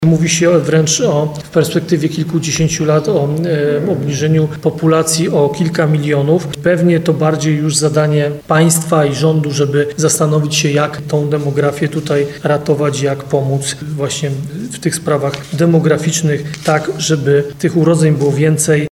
Do sprawy odniósł się prezydent Ludomir Handzel, który podkreślił, że w Nowym Sączu widać bardzo niekorzystną tendencję, która od lat ma miejsce w całej Polsce.